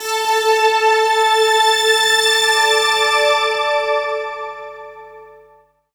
strTTE65016string-A.wav